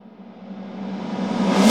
Techno / Drum / TOM001_TEKNO_140_X_SC2.wav